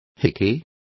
Complete with pronunciation of the translation of hickey.